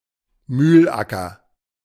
Mühlacker (German pronunciation: [ˈmyːlˌʔakɐ]